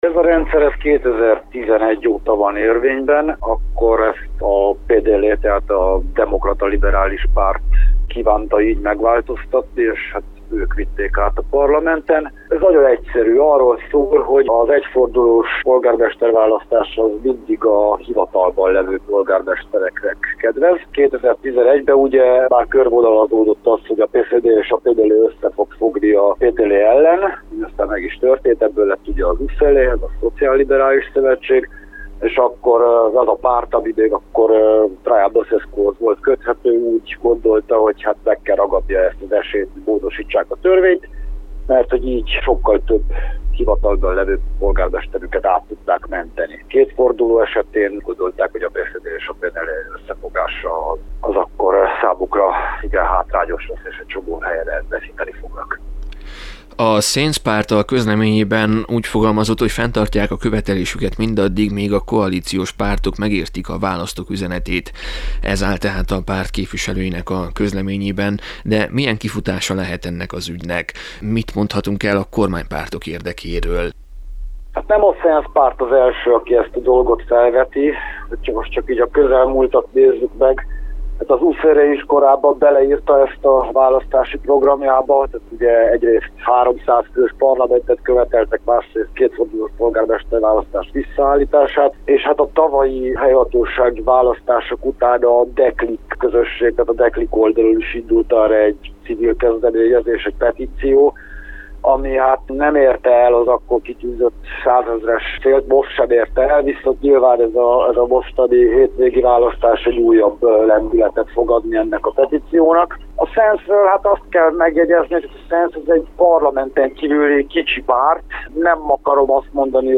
Politikusokat és politológust is megkérdeztünk a témában.